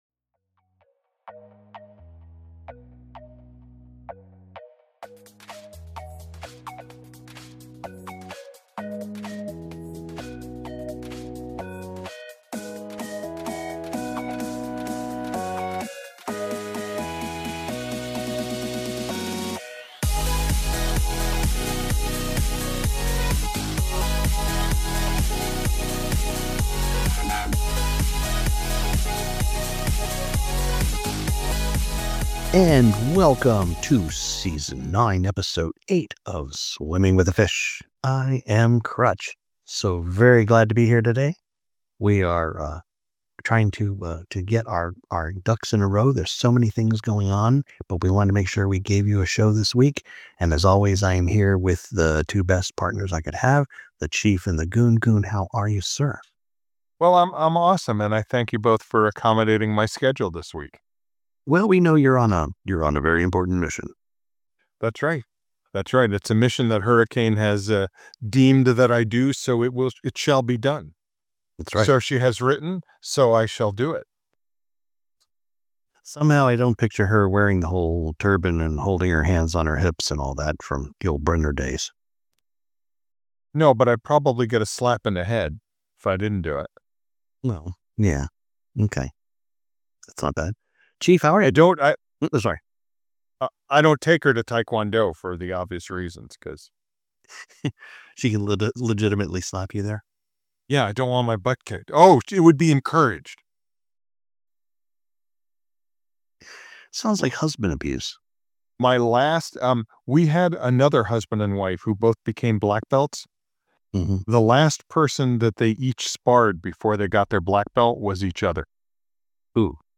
Three guys who like to get together weekly and discuss current events, politics, conspiracy theories and stuff that makes us laugh